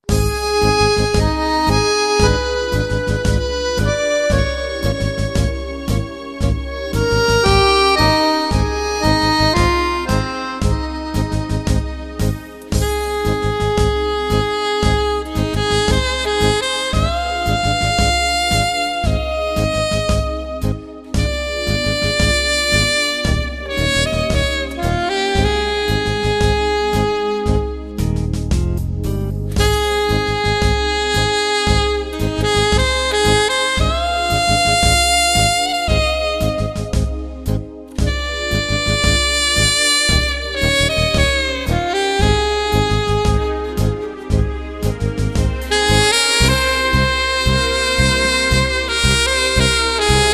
Tango bolero